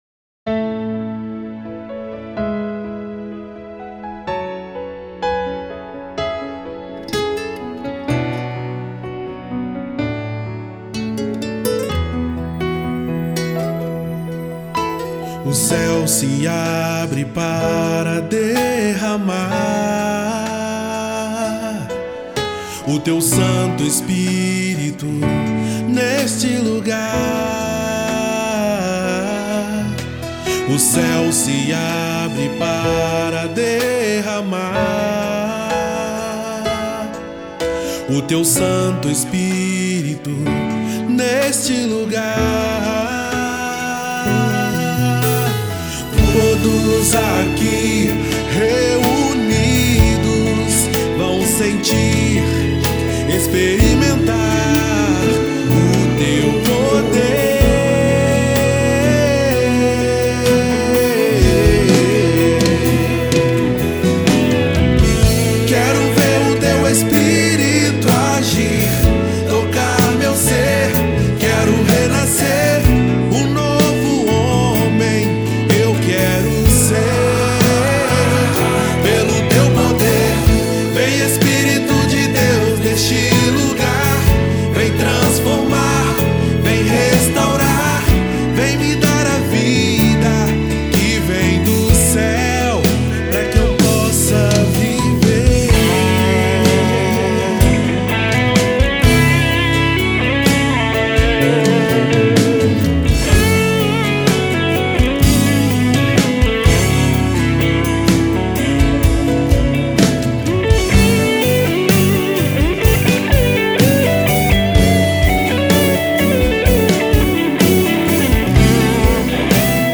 EstiloCatólica